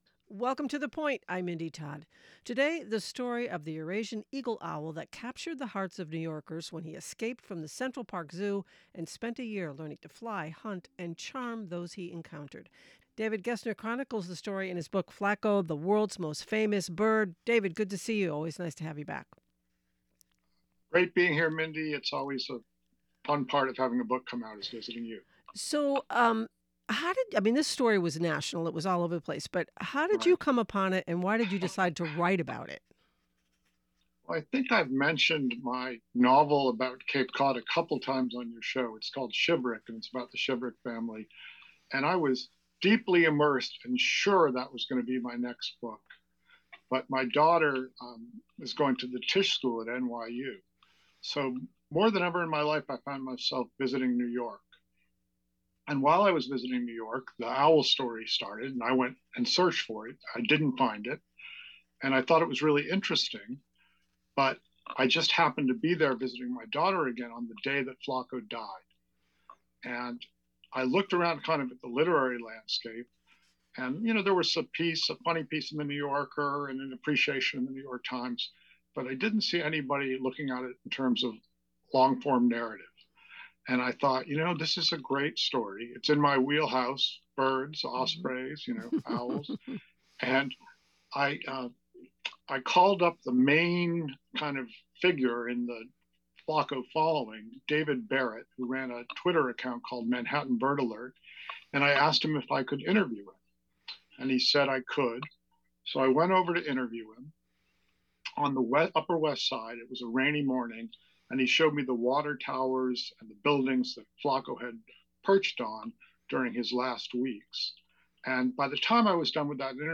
we dive into a powerful conversation